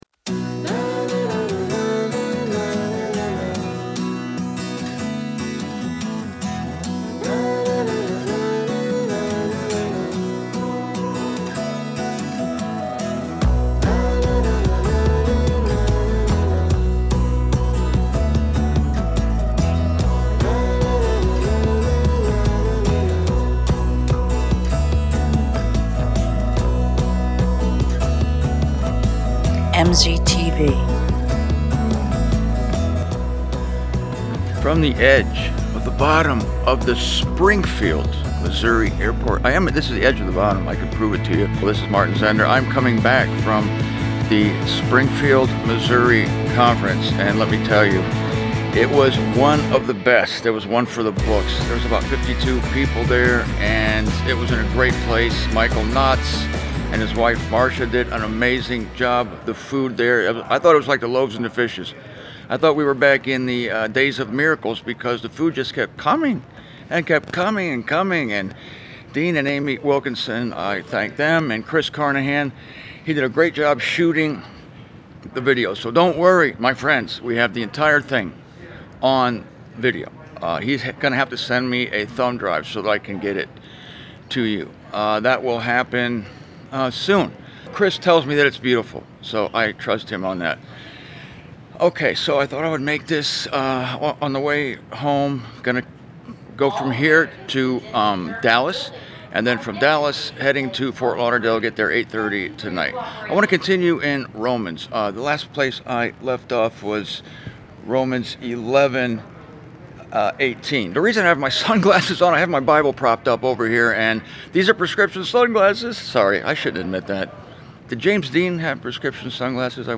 MZTV 549: The Mercy of God/Conference Report From the Airport